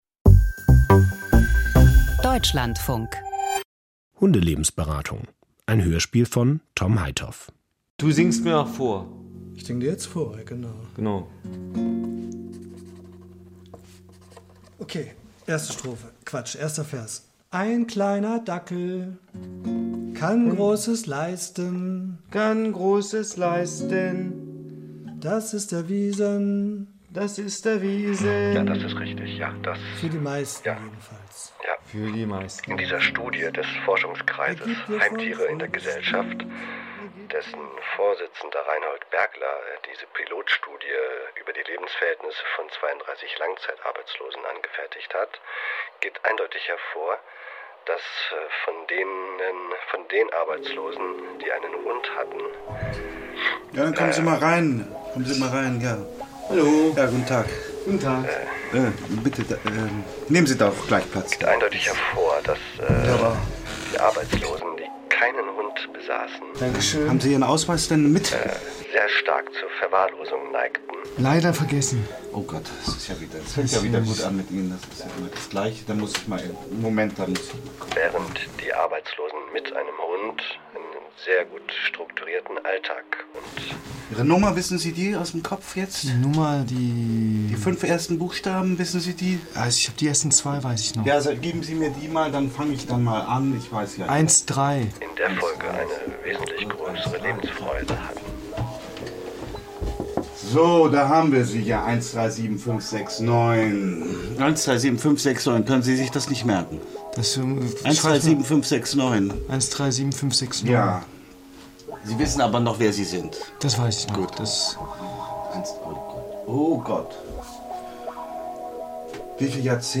Hörspiel: Ein Hund für Hartz-IV-Empfänger - Hundelebensberatung